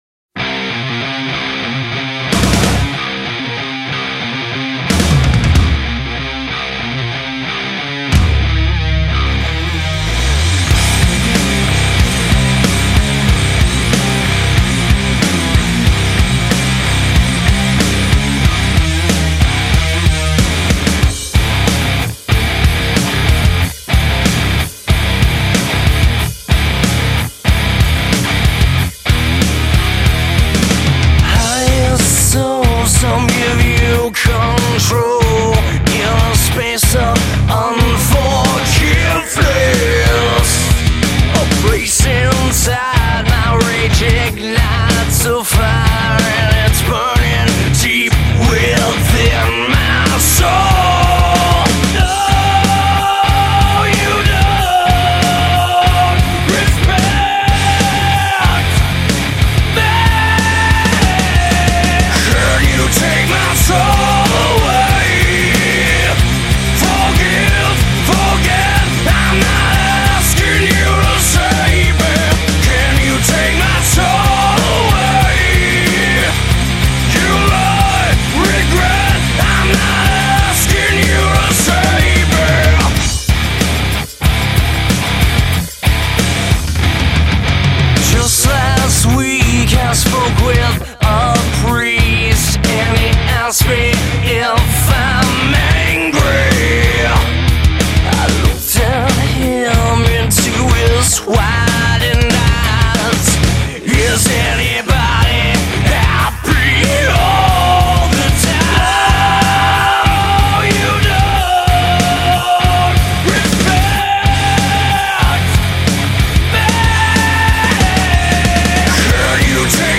As the name already suggests, its hard rock.
Sometimes I just like a more simpler heavy sound.